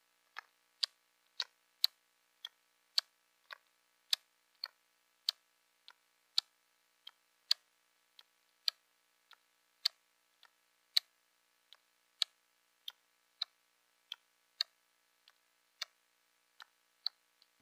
Here are a couple of the same sound bites (click on one of the two buttons below) of the Best Upgraded new firm Tactical Gold fire button upgraded Silicon round pads being tested on a used upgraded CX78 JoyPad.  As each CX78 fire button was pressed down, it would reach a point in its downward direction / travel and fire button round silicone pad would snap down (1st tactical feed back feel) the Gold contact against the inner PCB board, this is the 1st lower pitch sound on the sound bite.
The second louder / higher pitch sound is when the released CX78 red plastic fire button hits the inside of the CX78 top case.
CX78 Fire button test 12.1.wav